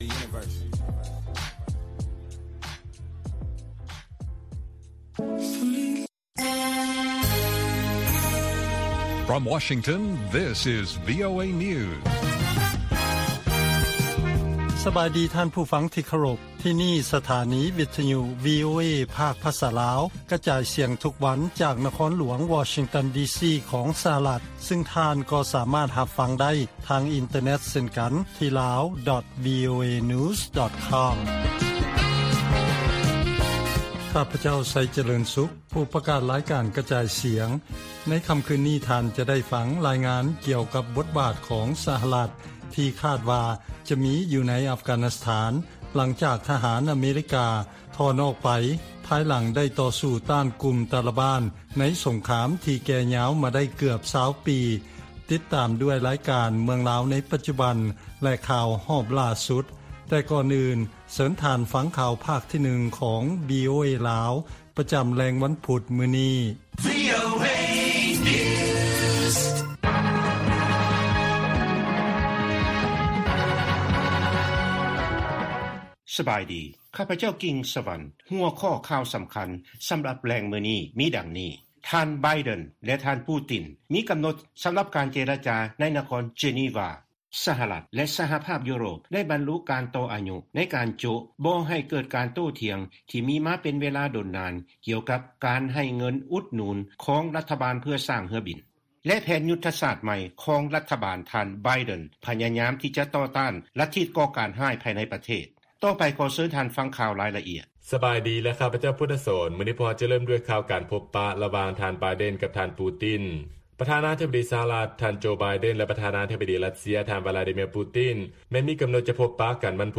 ລາຍການກະຈາຍສຽງຂອງວີໂອເອ ລາວ: ເກົາຫຼີເໜືອໃຫ້ຮ່ອງຮອຍວ່າ ຈະ 'ແກ່ຍາວ' ການປິດປະເທດຍ້ອນໂຄວິດ-19